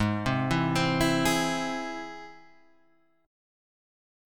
G# Augmented